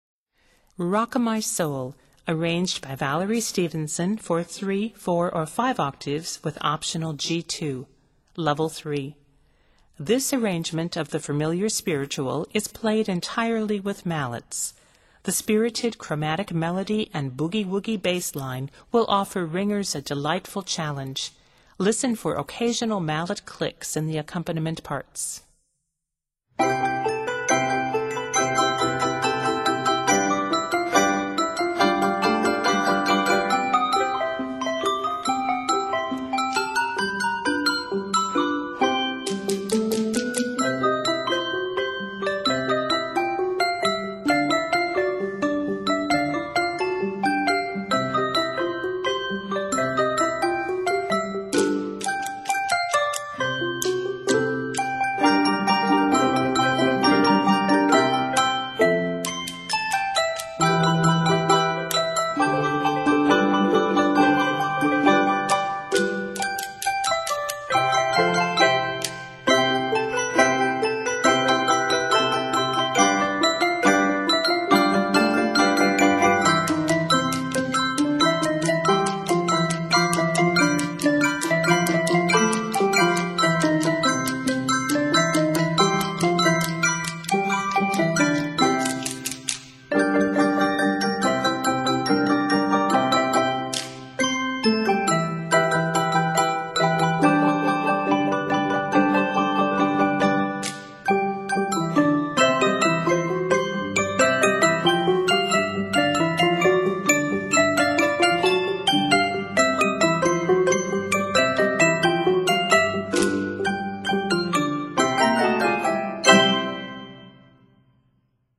Arranged in C Major, this piece is 51 measures.